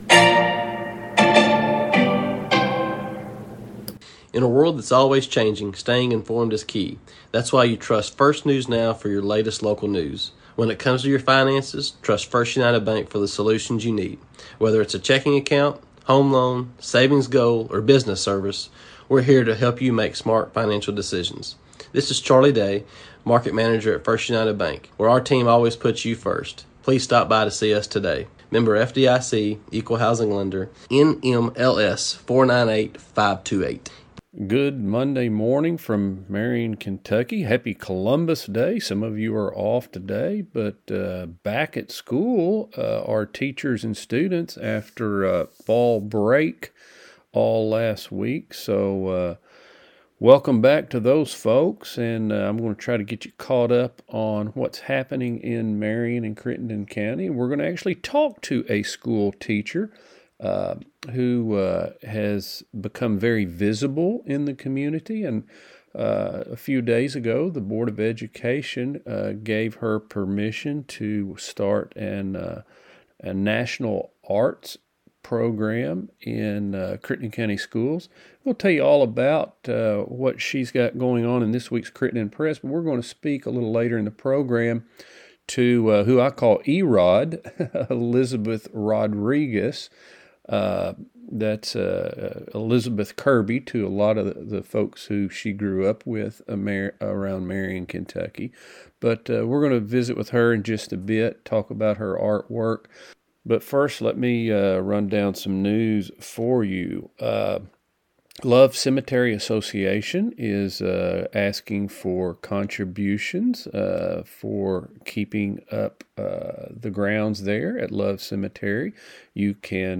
News | Sports | Interview